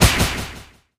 fang_atk_hit_01.ogg